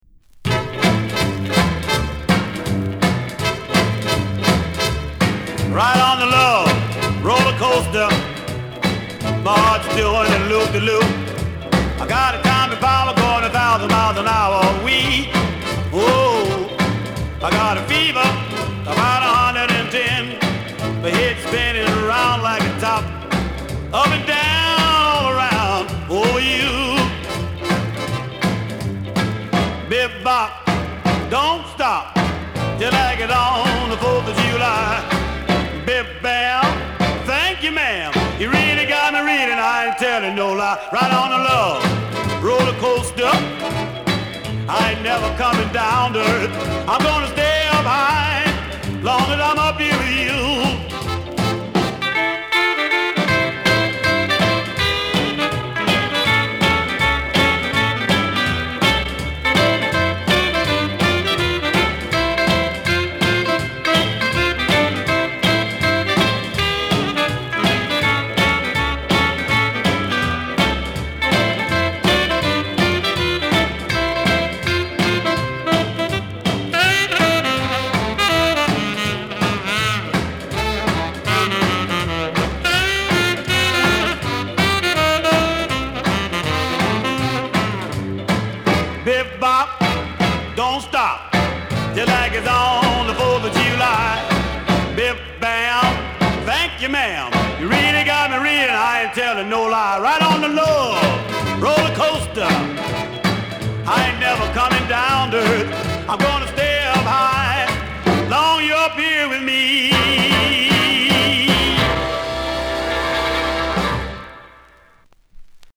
こちらは緩やかなリズムで進行するスウィンギーなムードに溢れた傑作チューン。